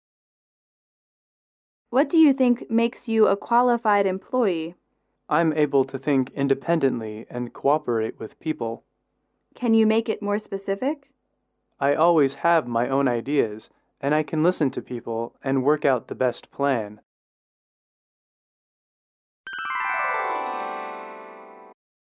英语口语情景短对话22-2：工作面试(MP3)